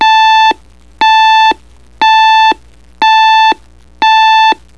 Index of /alarms
reveil3.wav